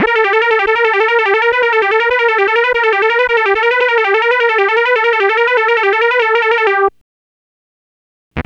Synth 19.wav